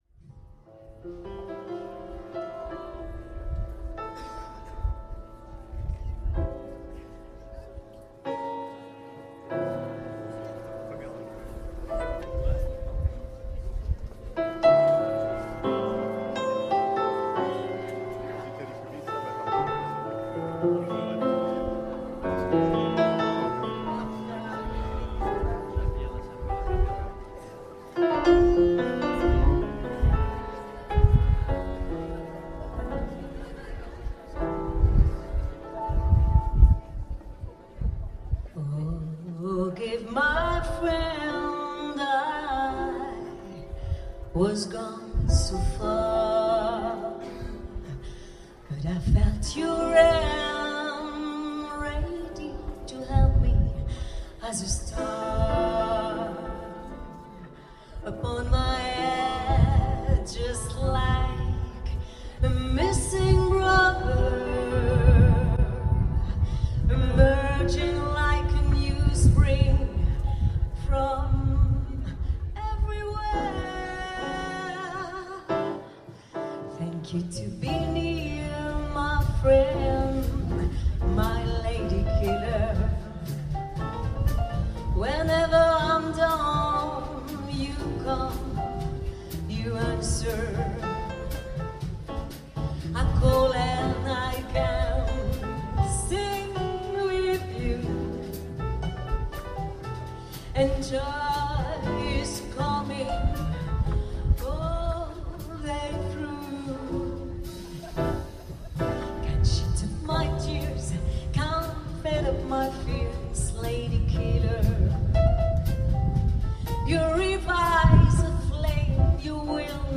vocal
piano
contrebasse
batterie.